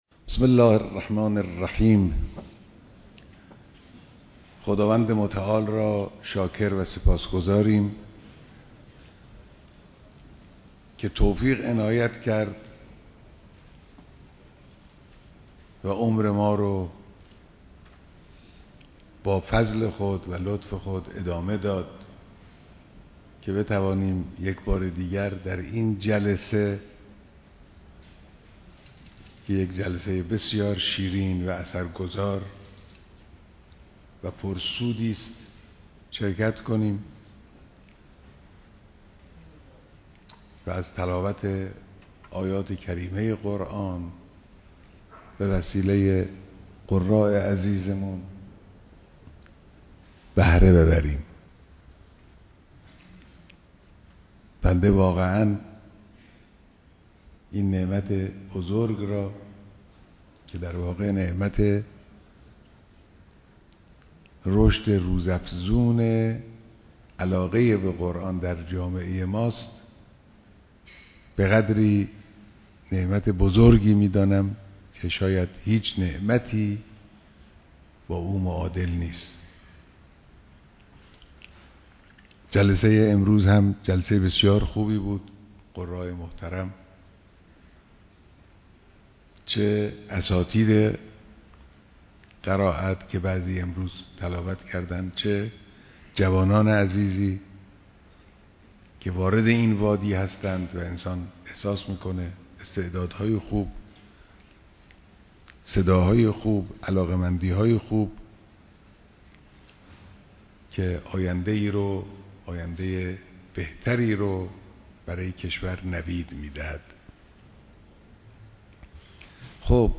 برگزاری محفل معنوی ضیافت نور و انس با قرآن در حسینیه امام خمینی (ره)
بیانات در ضیافت انس با قرآن در اولین روز ماه رمضان